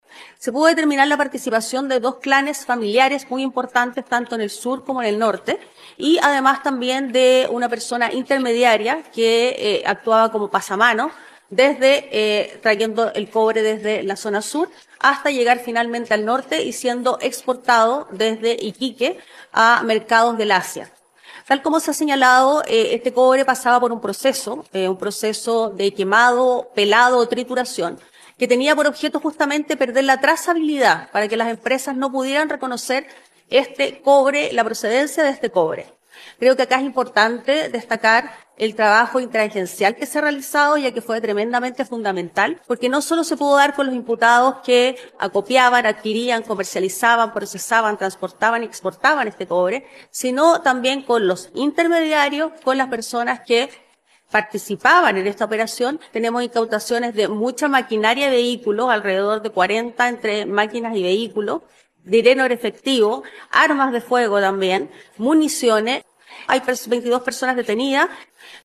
Como se indicó esta semana, la indagatoria nacional permitió además de incautar más de 187 toneladas de cobre, también dinero en efectivo, armas, vehículos de alta gama y maquinaria, dijo la fiscal (S).